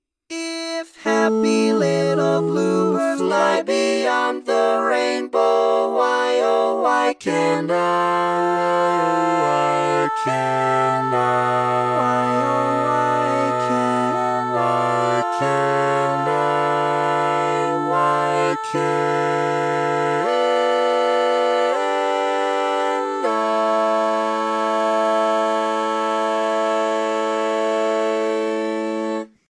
Key written in: A Major
Type: SATB